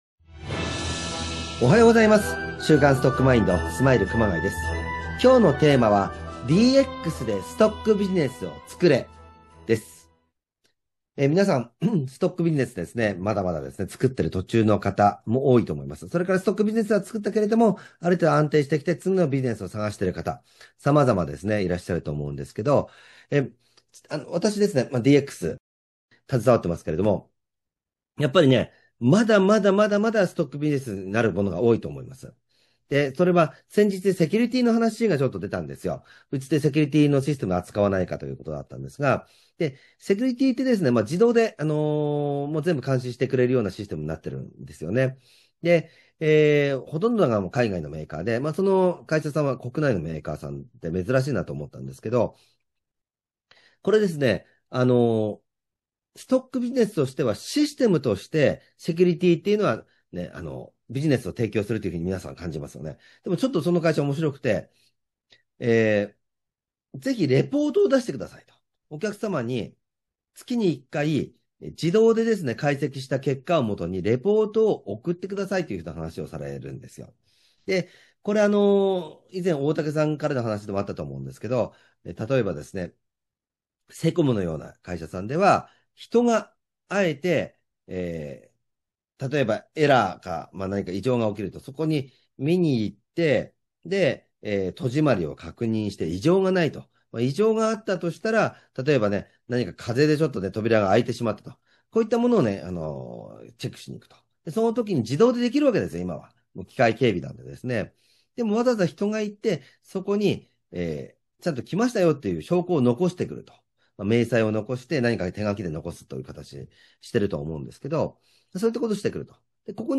音声番組「週刊ストックマインド」。